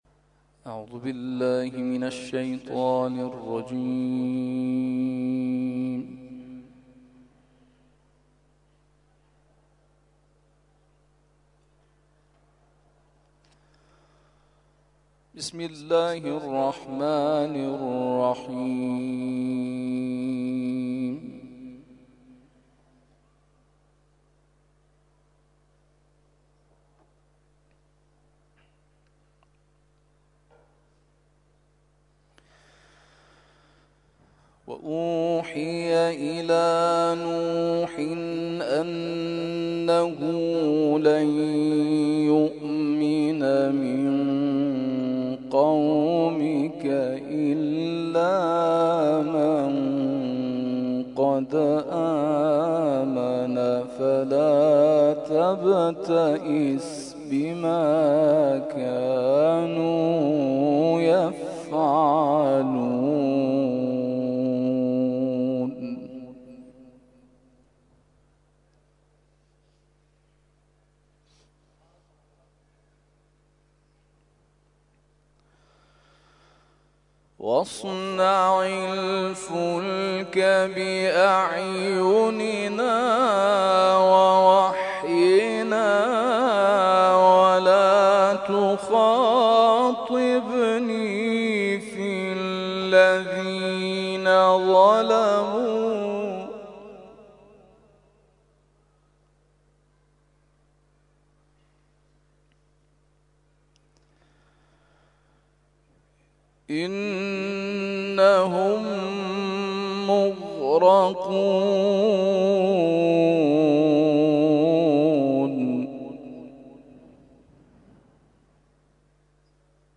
تلاوت صبح